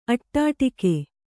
♪ aṭṭāṭike